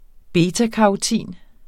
Udtale [ ˈbeːtakɑoˌtiˀn ]